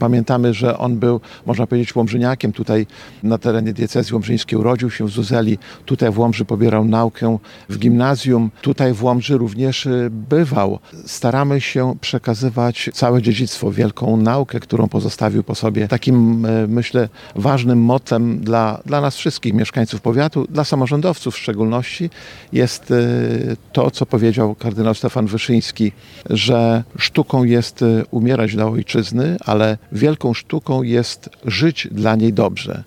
To dla nas zaszczyt, że patronuje nam tak wielki Polak – podkreśla starosta łomżyński – Lech Marek Szabłowski: